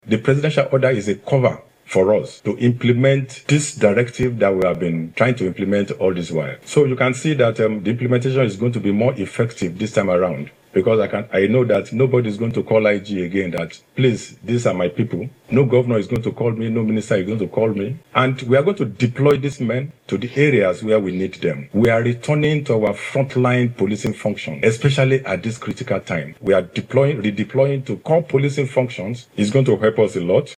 Speaking at a meeting with Police Strategic Commanders in Abuja, Egbetokun said the directive of the president has shut the doors for governors or ministers, to influence officers deployment.